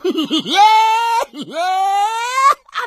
PigLaugh 02.wav